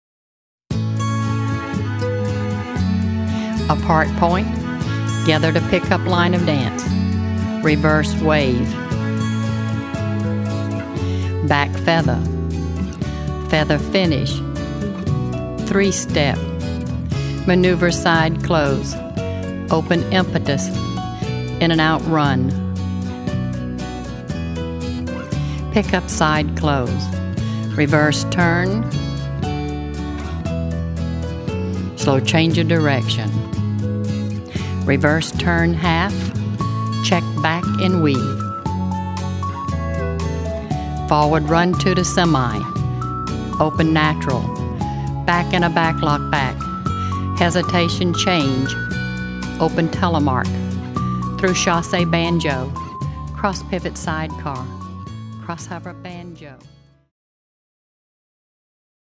Foxtrot